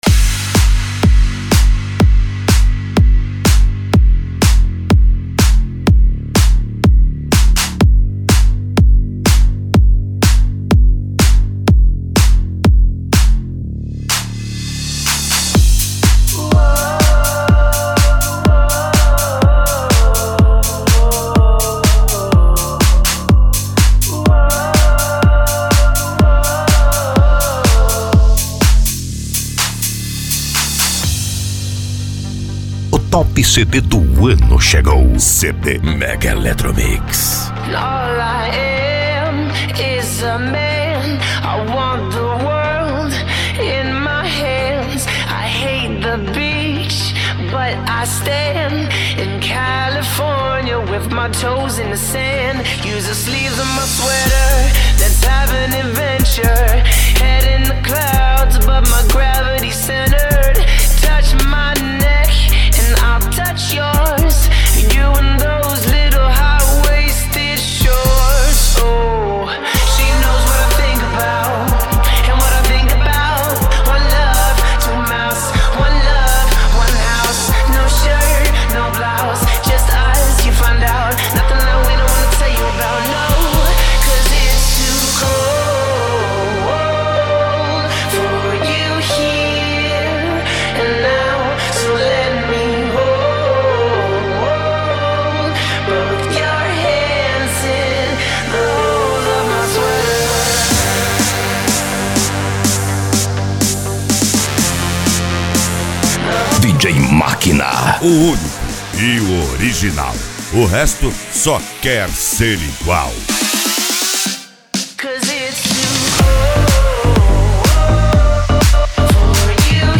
Electro House
Minimal
Psy Trance
Remix